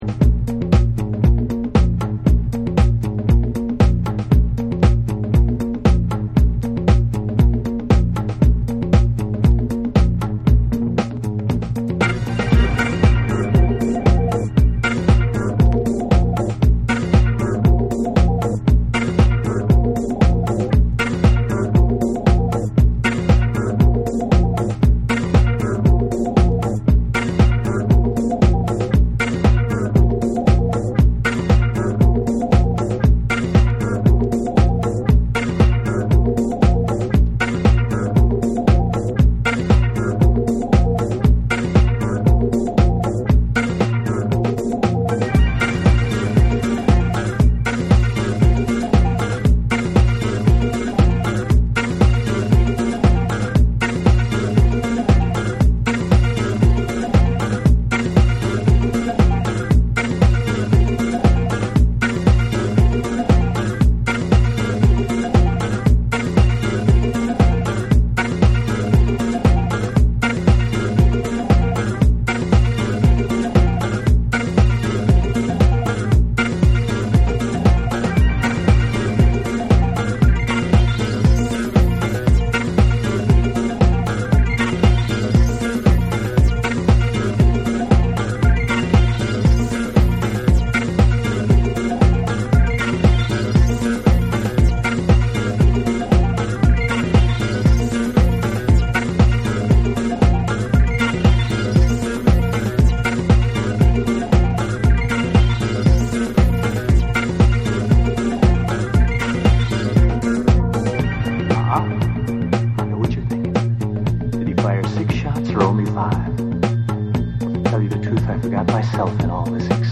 ディスコ・ダブともリンクする飛び系効果音やサイケデリックなエフェクトが絡んだコズミック・ブギー
アフロ〜ジャズ〜ファンク、そしてダブの要素が渾然一体となる
絶品人力ダブ・ファンクを全3曲収録。
BREAKBEATS / ORGANIC GROOVE